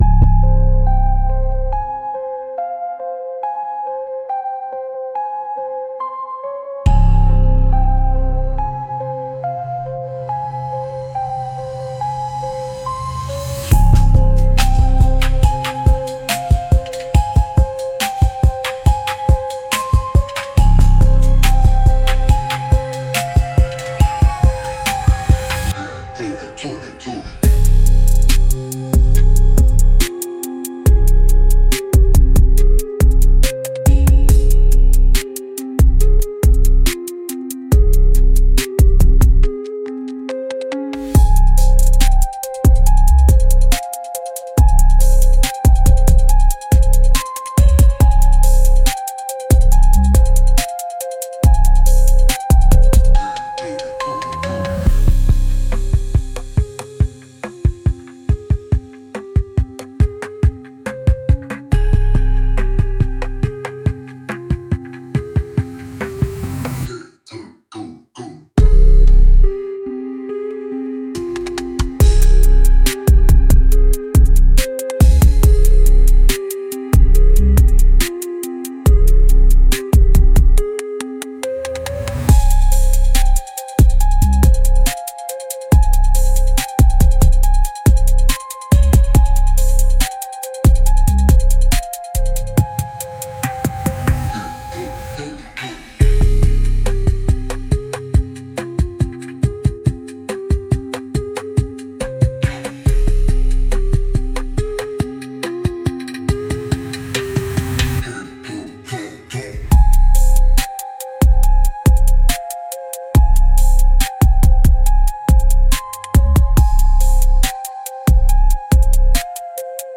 Instrumental - Real Liberty Media DOT xyz-- 2.47 mins